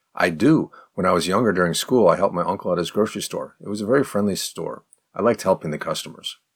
Spoken fast:
03_advanced_response_fast.mp3